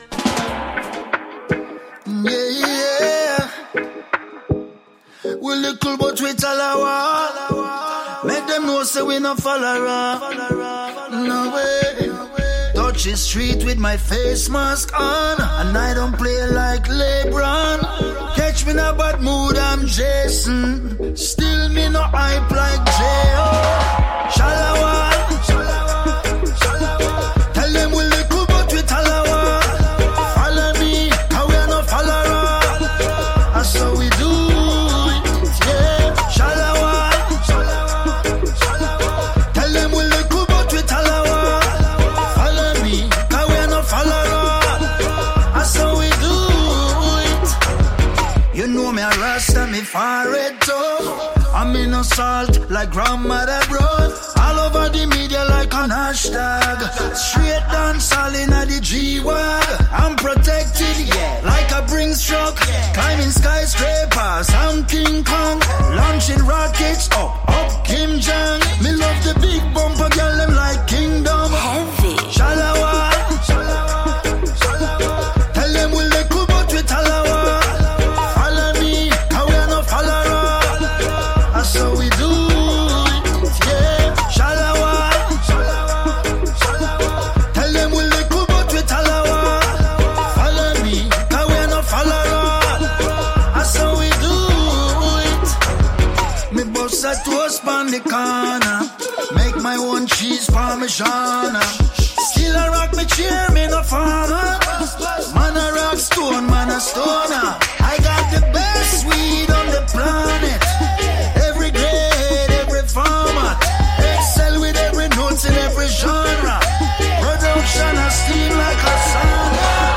ARTISTA A LA VISTA | INTERVISTA ALBOROSIE | Radio Città Aperta
Artista-a-la-Vista-Intervista-Alborosie.mp3